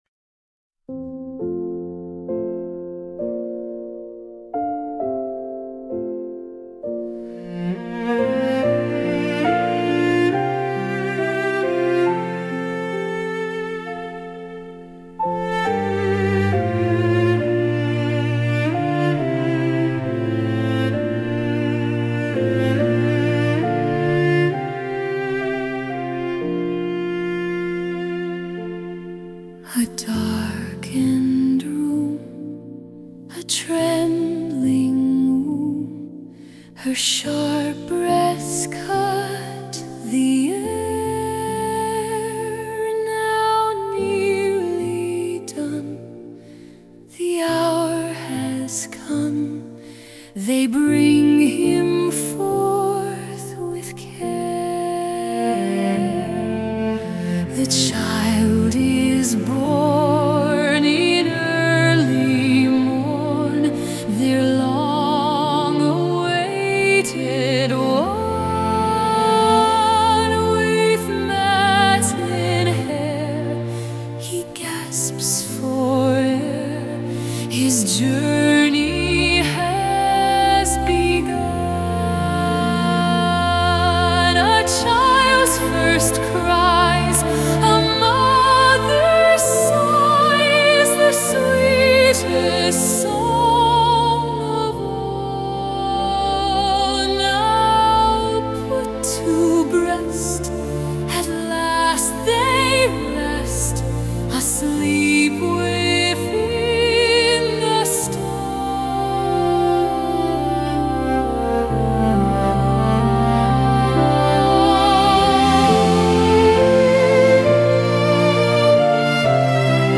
I attempted to try using AI (Suno software) to produce an audio version. I uploaded a piano version to keep Suno from going to far afield with the melody.
Nevertheless, you can get a taste of the song—reverential, calm, and beautiful.